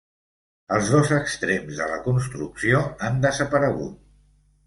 Pronounced as (IPA) [ˈdos]